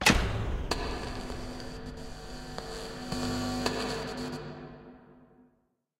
concertFail.ogg